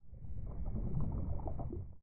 Add footsteps for water - swimming sounds.
I've taken a single, longer audio sample and extracted 3 samples of 2.0 length, and gave them all a 0.5 sec fade-in and fade-out.
This combined with the rate of footstep sounds results in a randomly changing underwater sound that blends in and out somewhat nicely.
default_water_footstep.2.ogg